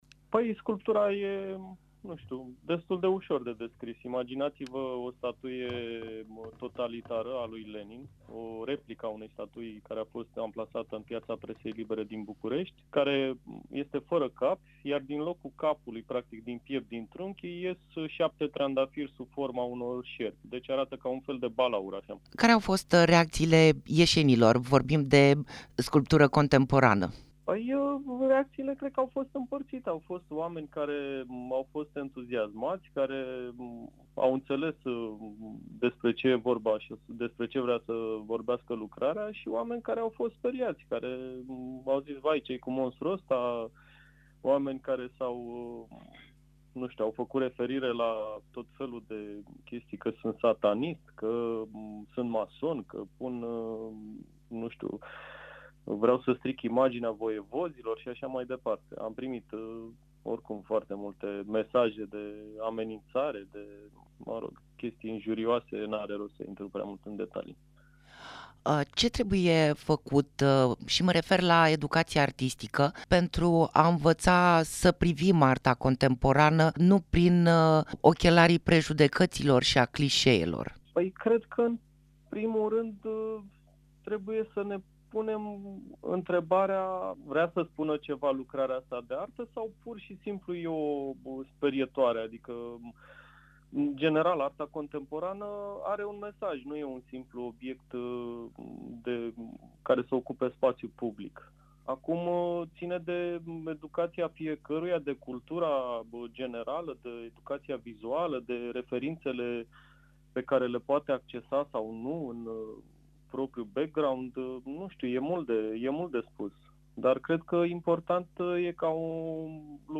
Interviu-sculptor-Hidra.mp3